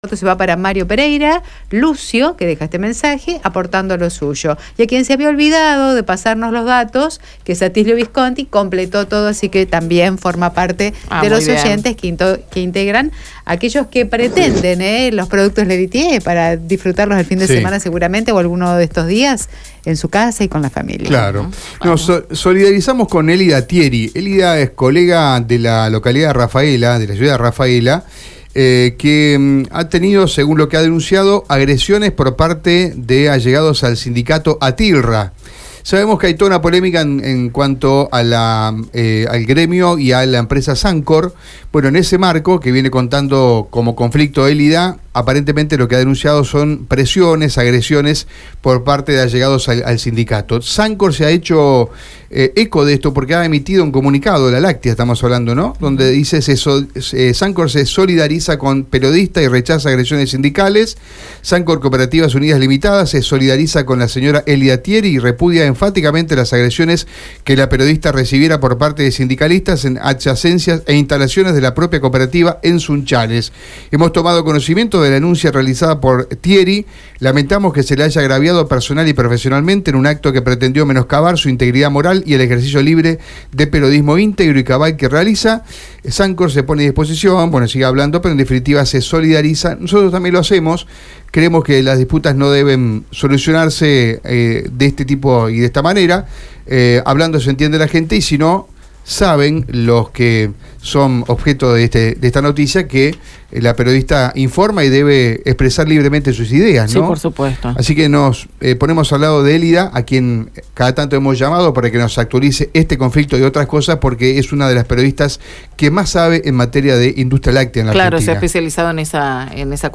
En la ciudad de Santa Fe la protesta se concretó en la sede de la Secretaría de Trabajo de la Nación ubicada en la Peatonal San Martín al 2500,